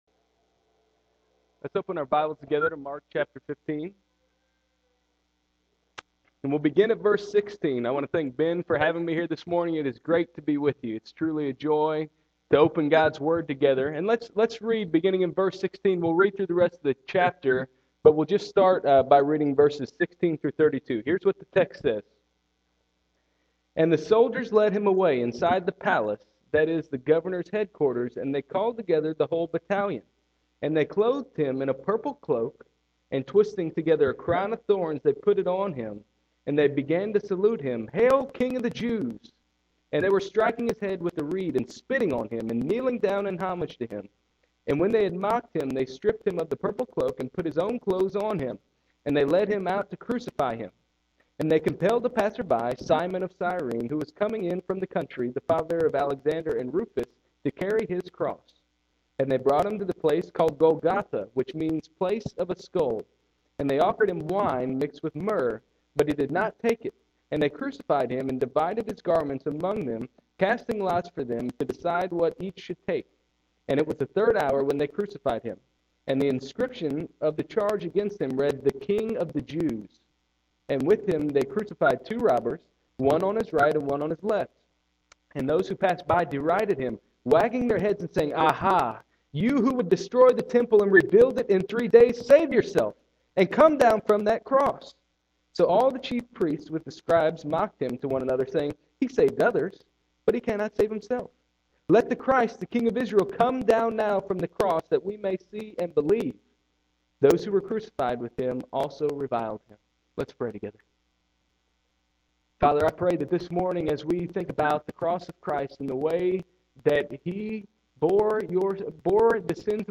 November 29, 2009 AM Worship | Vine Street Baptist Church